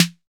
SNR 808 SN00.wav